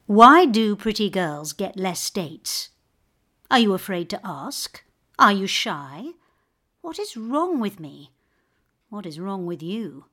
‘Touch’ and listen to the emotions and thoughts of this heartbroken woman.